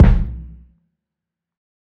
HFMKick8.wav